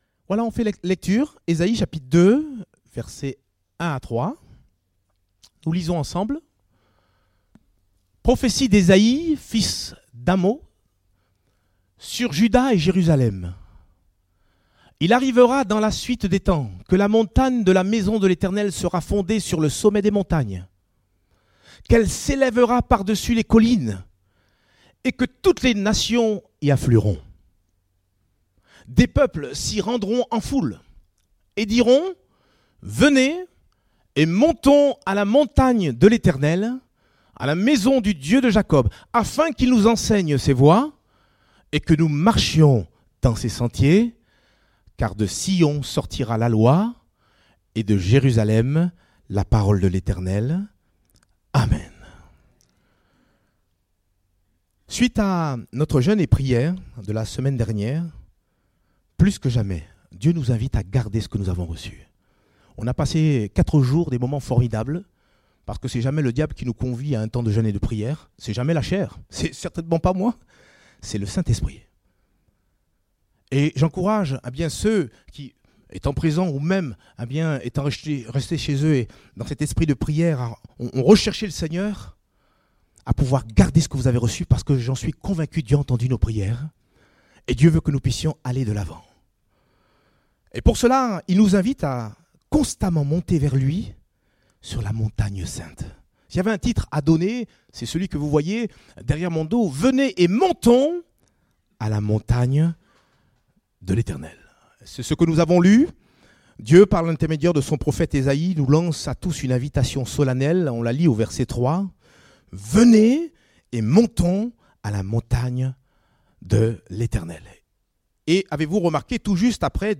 Date : 28 janvier 2018 (Culte Dominical)